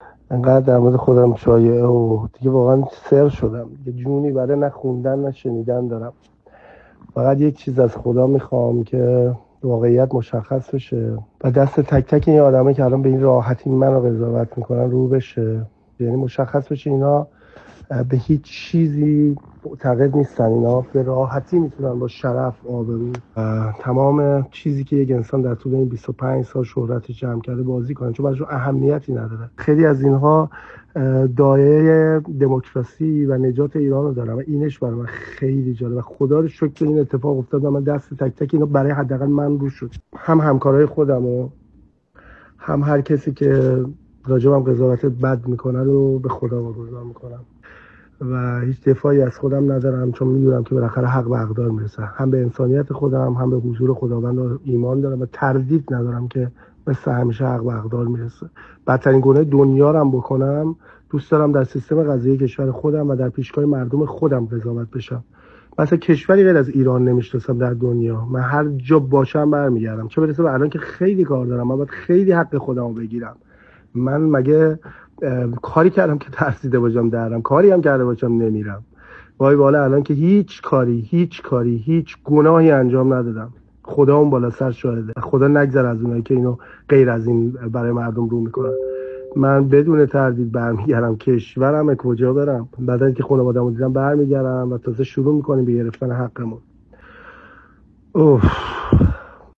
پژمان جمشیدی که با وثیقه از زندان بیرون آمد و اخیرا تصویری از او در فرودگاه کانادا منتشر شد، در پیامی صوتی اعلام کرد برمی‌گردد و حقش را می‌گیرد.